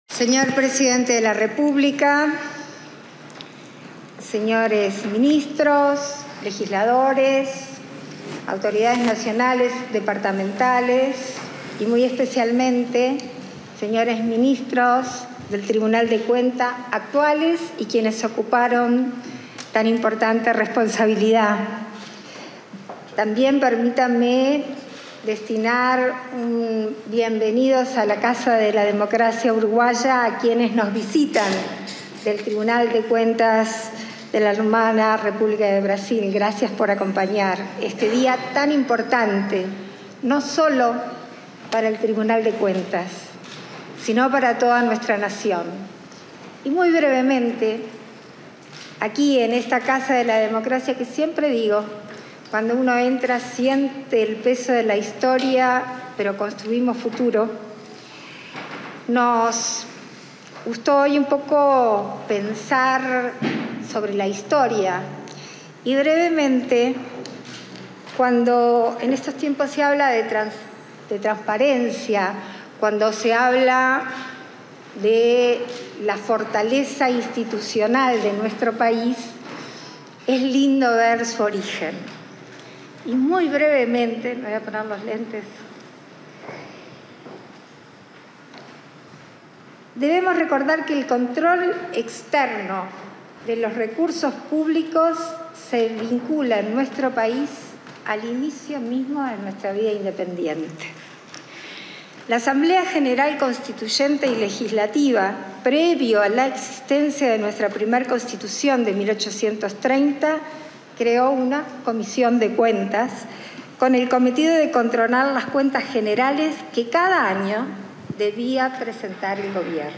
Palabras de la vicepresidenta de la República, Beatriz Argimón
Palabras de la vicepresidenta de la República, Beatriz Argimón 13/08/2024 Compartir Facebook X Copiar enlace WhatsApp LinkedIn En el marco de la conmemoración del 90.° aniversario del Tribunal de Cuentas, este 13 de agosto, se expresó la presidenta de la Asamblea General y vicepresidenta de la República, Beatriz Argimón.